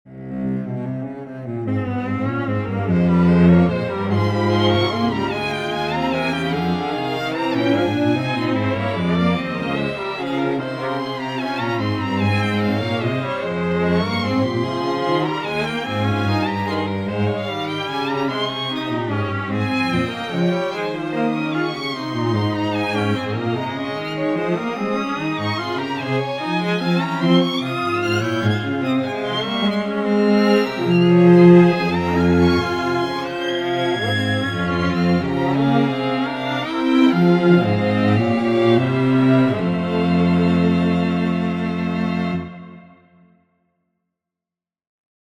Canon a 4 for String Quartet in F minor.